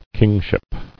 [king·ship]